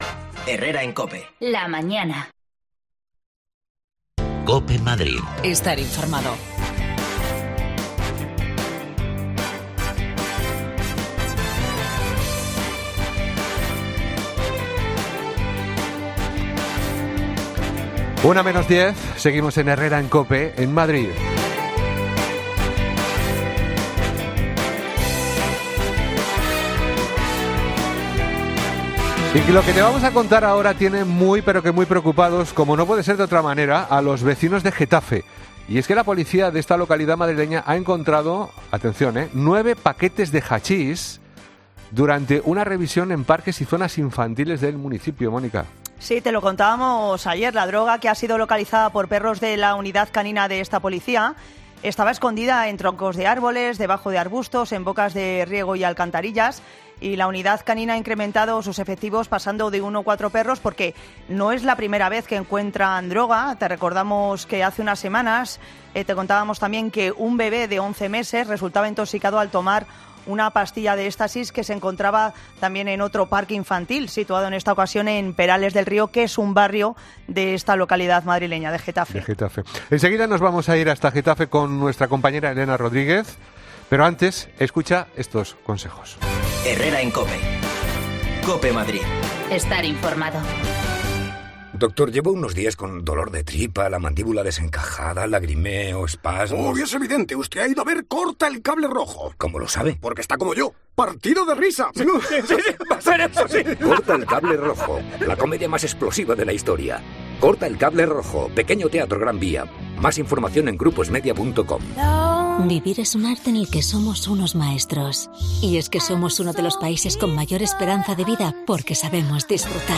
AUDIO: Los vecinos de Getafe están preocupados por la aparición, de droga en parques infantiles del municipio. Escuchamos algunos testimonios.